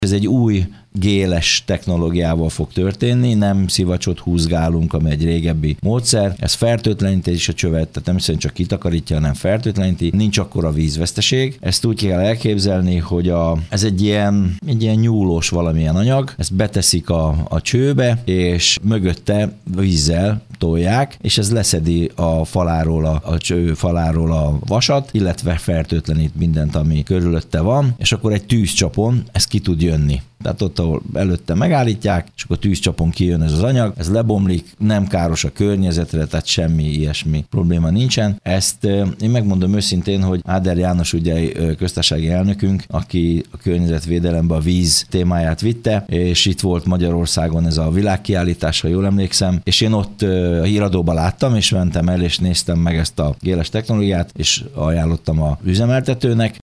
Elkezdődött a vízhálózat mosatása Gyálon. Egy új, zselés technológiával tisztítják a rendszert, amely nem tolja maga előtt a szennyeződést, hanem a gélben megköti azt, így a felhasználók nem tapasztalják az elszíneződést és az esetleges kellemetlen szaghatást. Pápai Mihály polgármestert hallják.